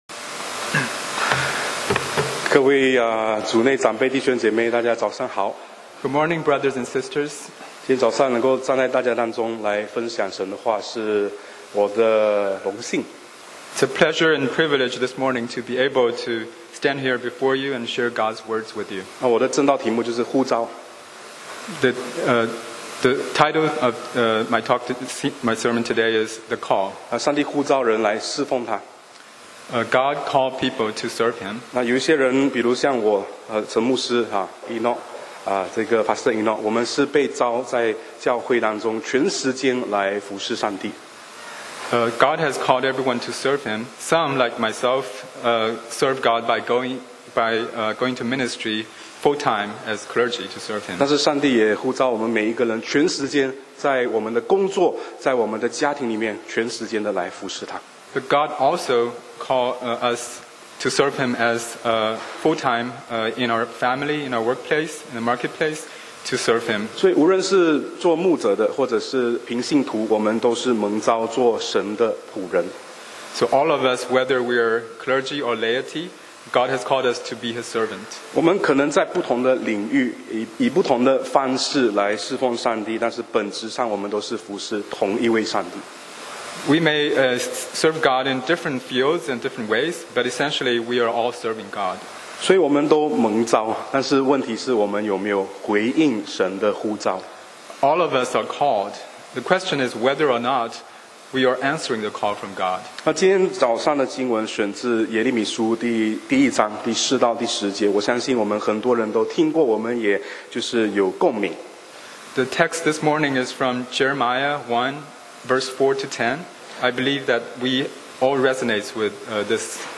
講道 Sermon 題目 Topic：《呼召》 The Call 經文 Verses：耶 Jer 1:4-10.4 耶利米說，耶和華的話臨到我說：5我未將你造在腹中，我已曉得你；你未出母胎，我已分別你為聖；我已派你作列國的先知。